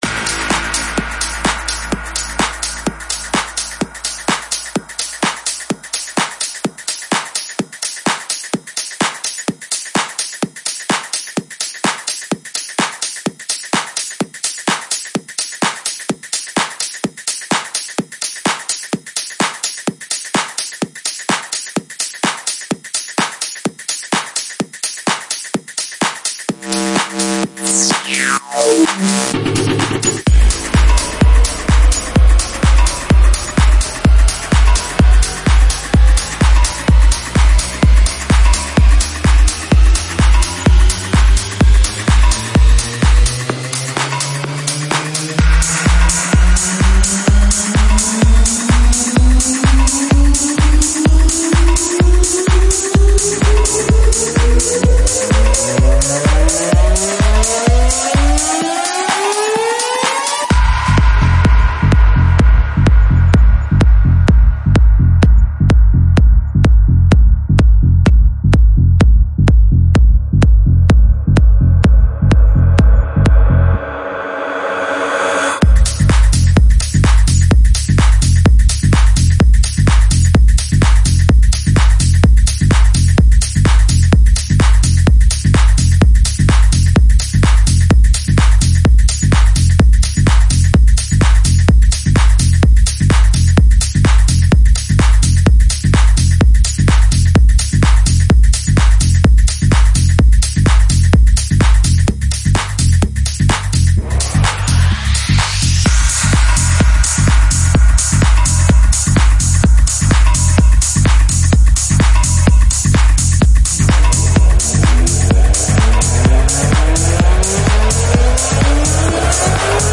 • Techno selection with tracks by